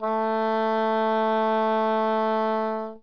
Oboe
oboe.wav